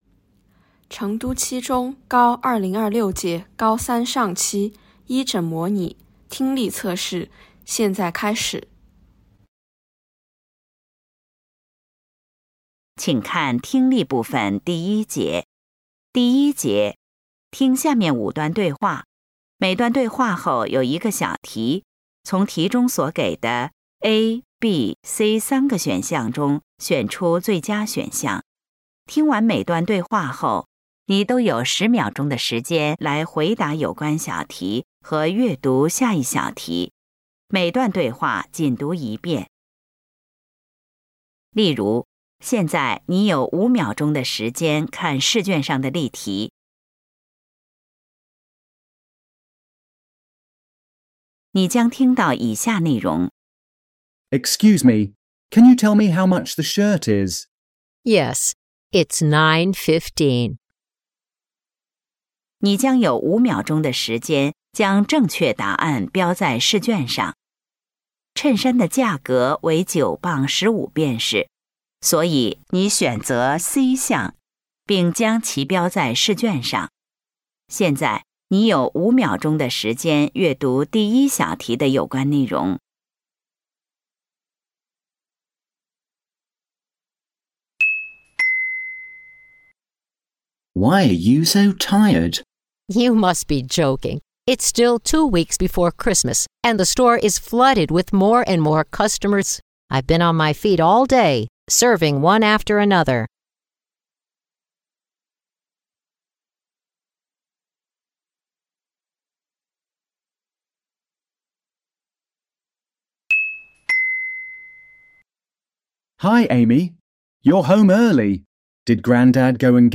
成都七中2026届高三一诊模拟检测英语听力.mp3